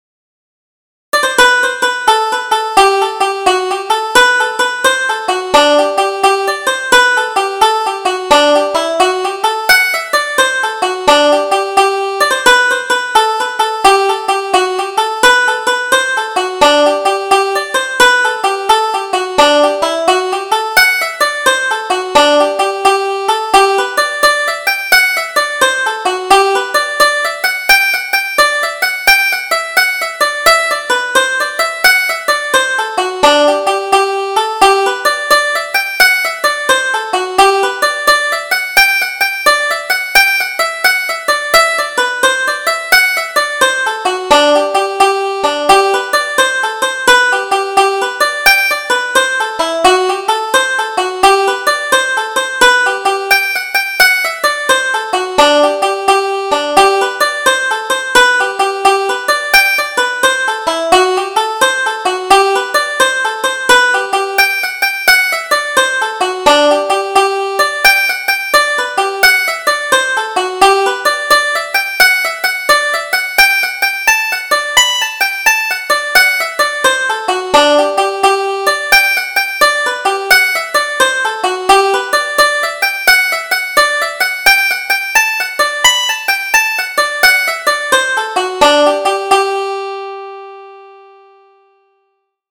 Double Jig: Kit O'Mahoney's Jig